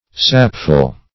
sapful - definition of sapful - synonyms, pronunciation, spelling from Free Dictionary Search Result for " sapful" : The Collaborative International Dictionary of English v.0.48: Sapful \Sap"ful\, a. Abounding in sap; sappy.